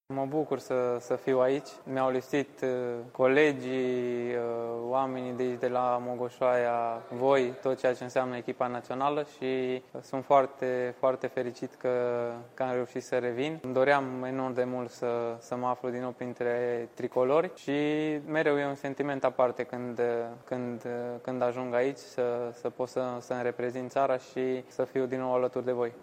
Nicolae Stanciu spune că este pregătit să-și asume rolul de lider în absența lui Vlad Chiricheș și că este fericit să se afle din nou la națională.